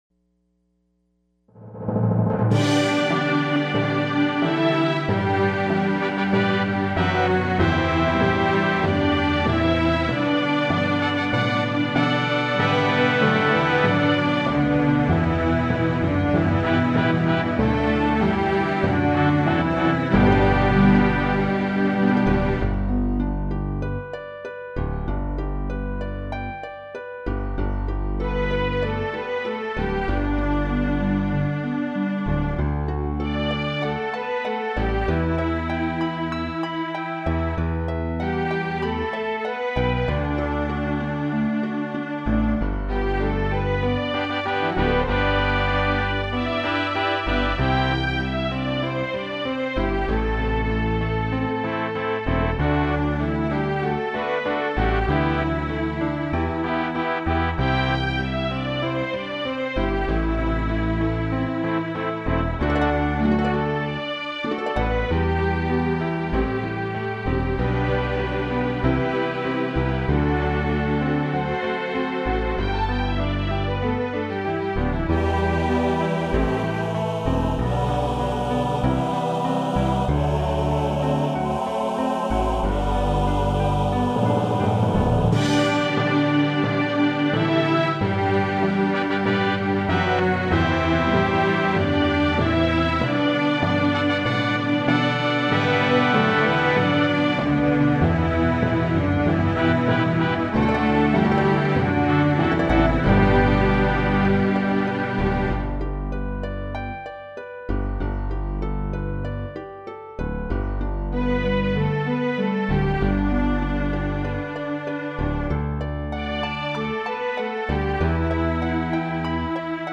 配乐(G调)Real格式在线收听(3分46秒)Real格式下载收听(924KB)